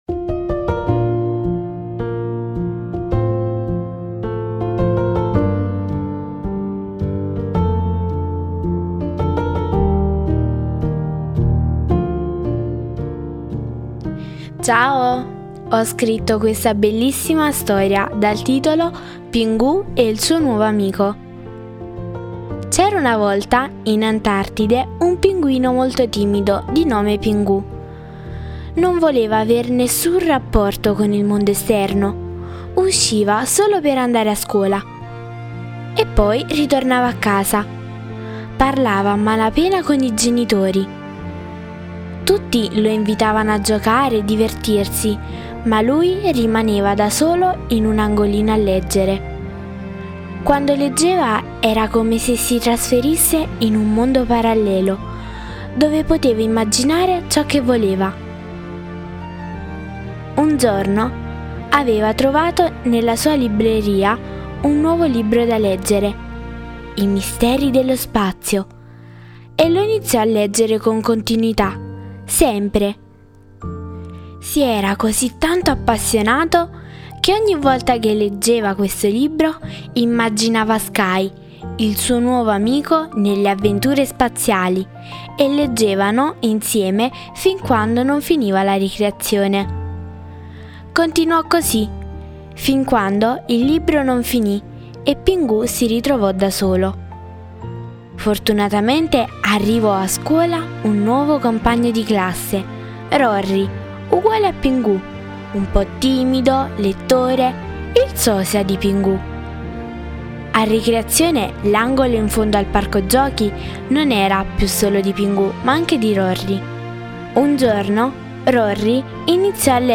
storia originale scritta e letta